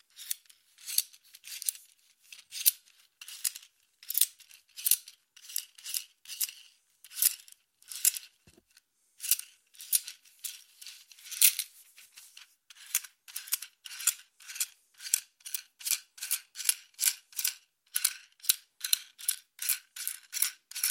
Звуки счётной машинки
Шуршание счётов деревянных при подсчёте